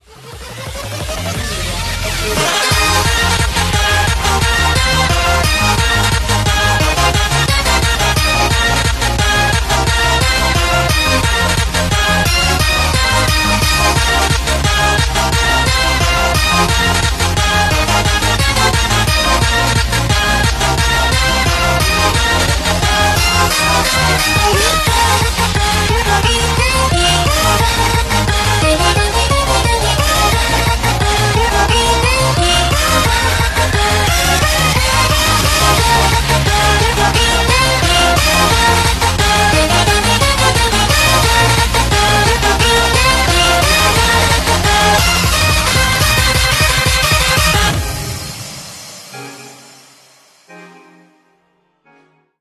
applause-psyqui.mp3